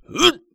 ZS格挡3.wav
ZS格挡3.wav 0:00.00 0:00.55 ZS格挡3.wav WAV · 48 KB · 單聲道 (1ch) 下载文件 本站所有音效均采用 CC0 授权 ，可免费用于商业与个人项目，无需署名。
人声采集素材/男3战士型/ZS格挡3.wav